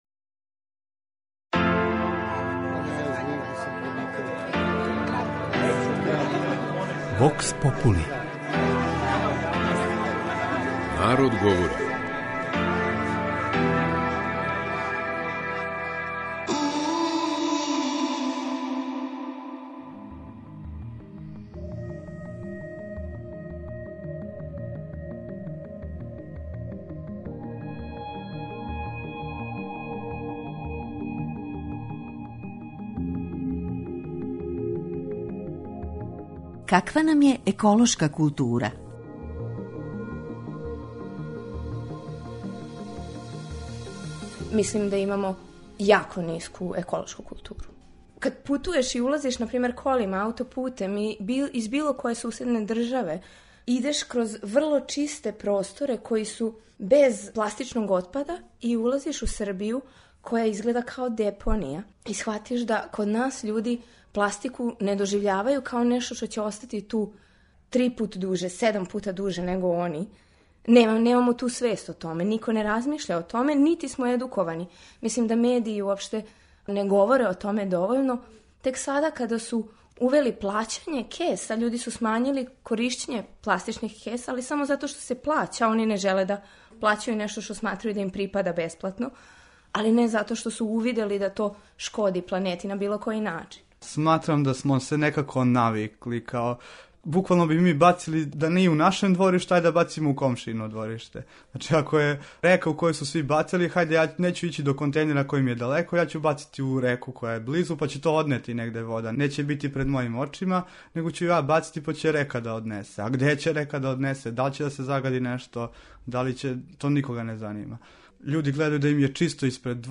У данашњој емисији своја размишљања и ставове о нивоу еколошке свести и културе код нас, са слушаоцима Радио Београда 2, поделиће грађани нашег главног града.